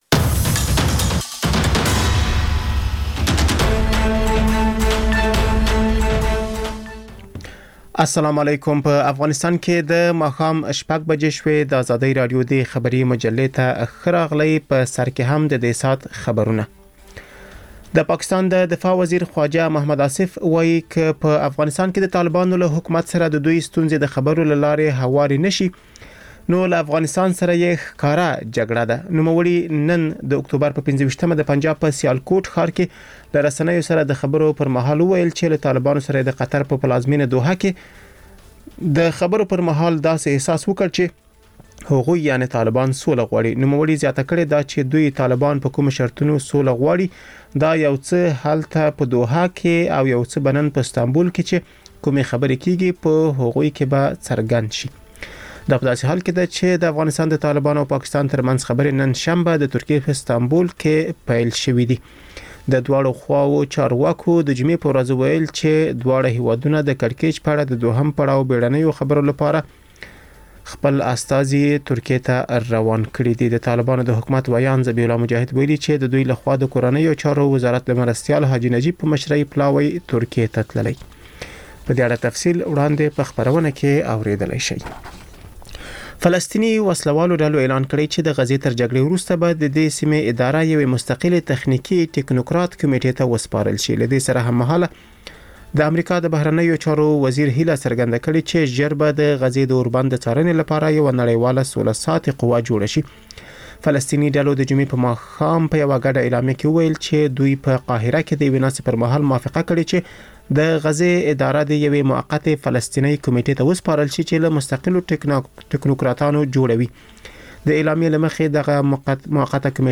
ماښامنۍ خبري مجله
ژوندي نشرات - ازادي راډیو